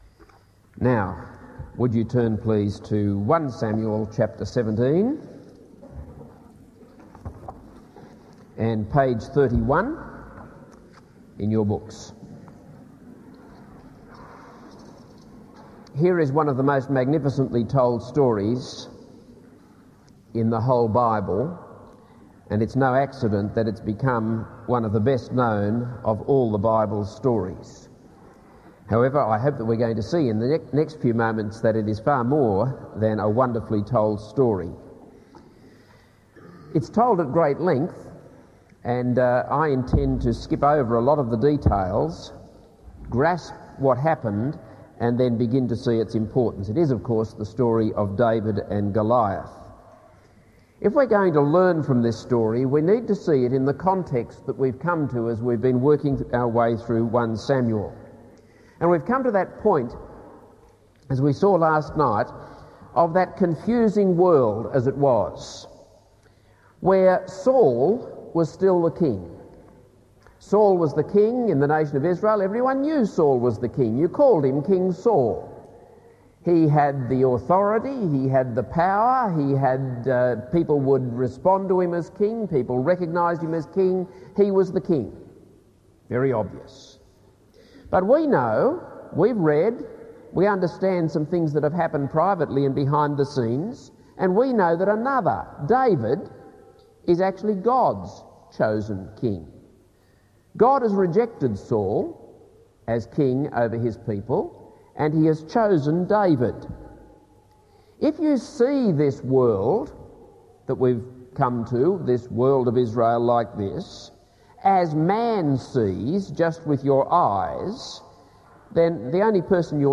This is a sermon on 1 Samuel 17.